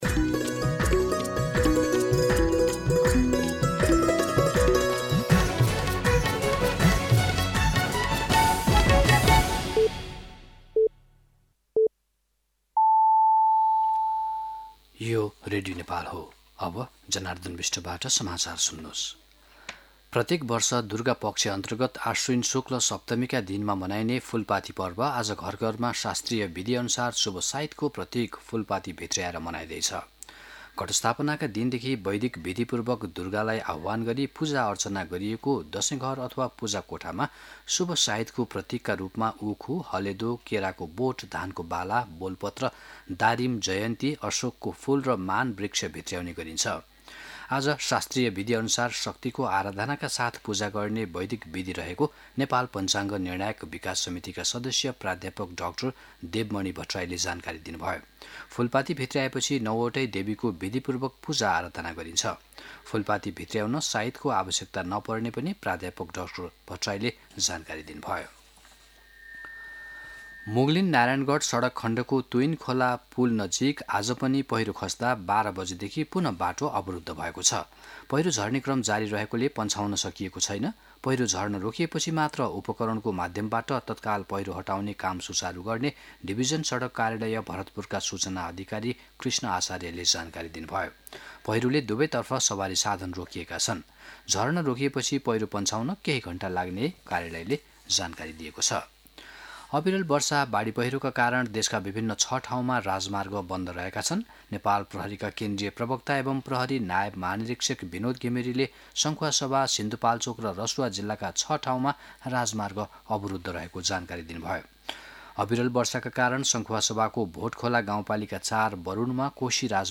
दिउँसो १ बजेको नेपाली समाचार : १३ असोज , २०८२
1-pm-Nepali-News-5.mp3